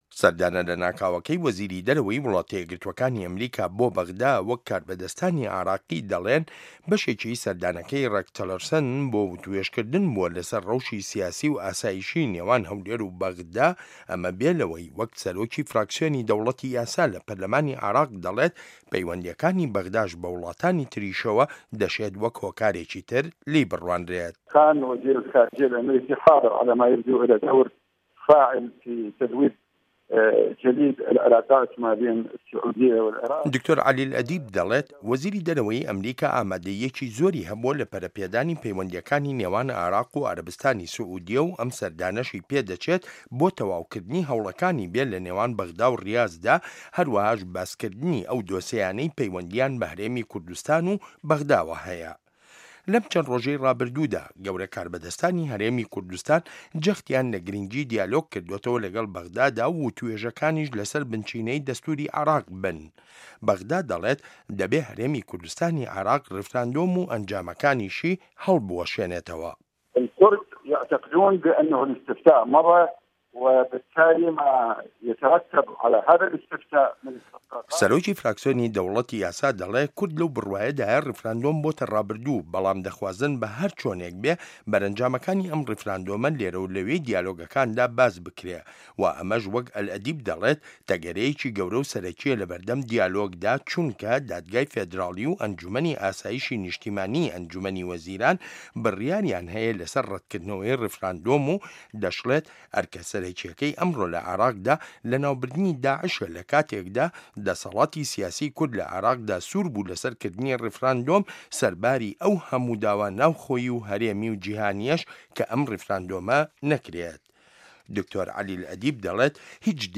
ڕاپۆرت لەسەر بنچینەی لێدوانەکانی دکتۆر عەلی ئەلئەدیب